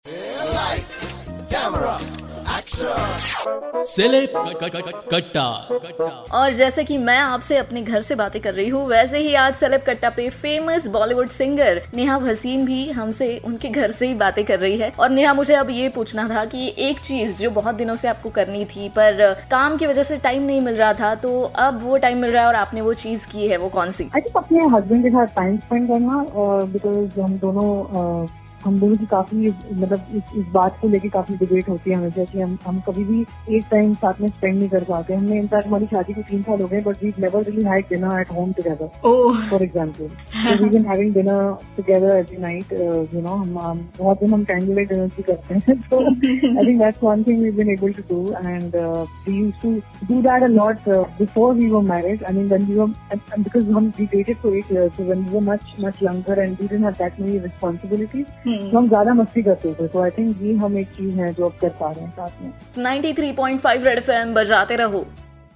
took an interview of famous Singer Neha Bhasin..In this interview she shared that because this lockdown she her husband got so much time together